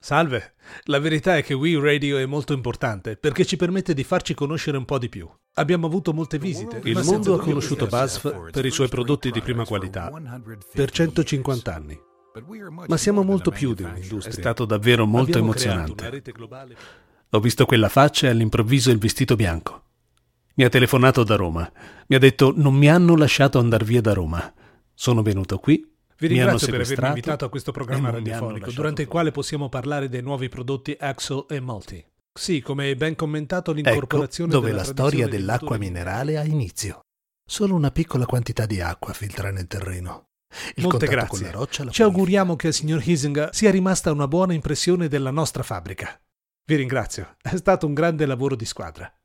Male
Adult (30-50), Older Sound (50+)
Impersonations
dubbing_demoreel
0923Dubbing_DemoReel.mp3